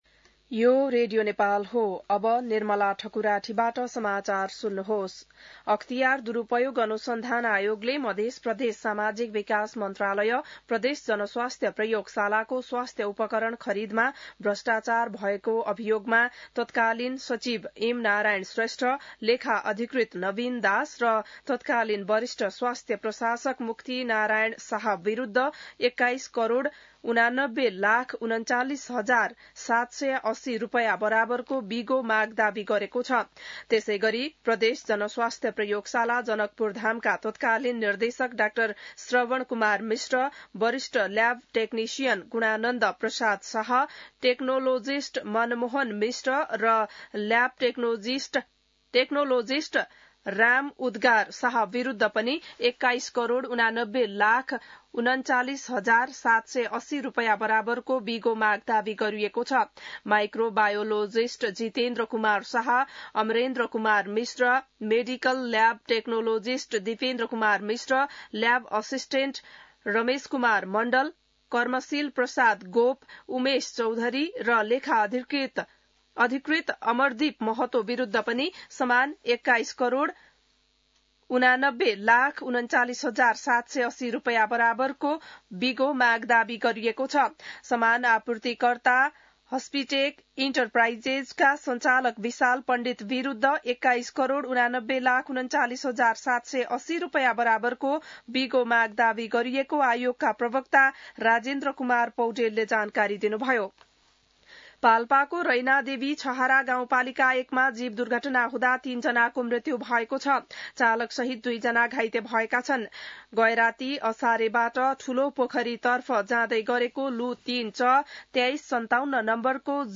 बिहान १० बजेको नेपाली समाचार : १५ वैशाख , २०८२